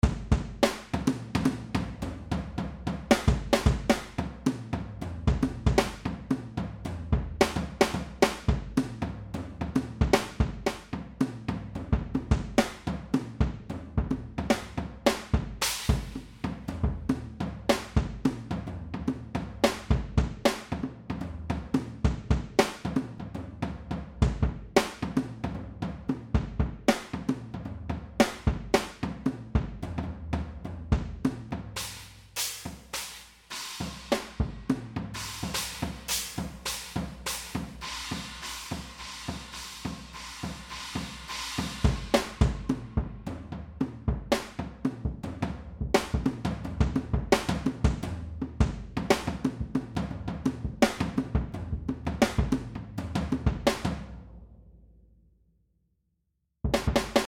Technik: Die Software besteht zum einen aus Samples, die in 3 sehr bekannten Studios eingespielt wurden.
Außerdem kann noch zwischen Sticks, Brushes und Rods, sowie zwischen Felt und Plastic gewählt werden.